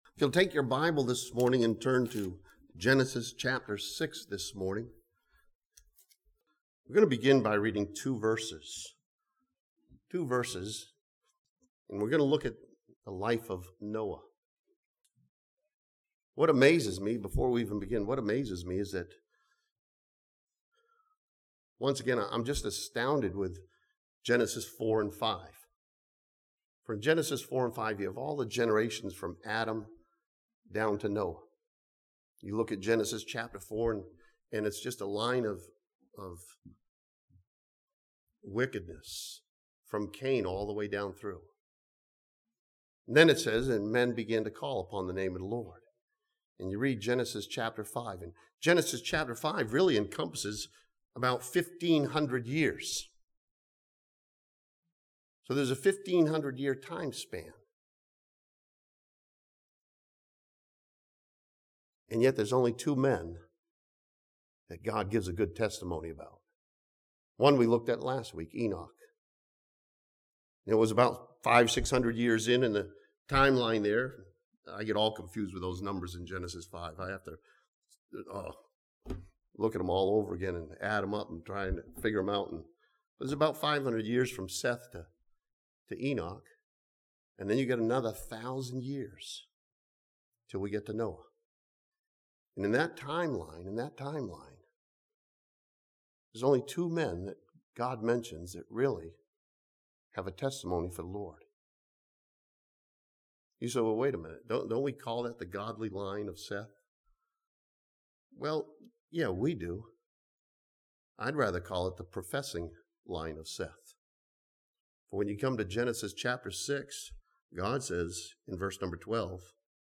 This sermon from Genesis chapter 6 studies the life of Noah as the right time to live right.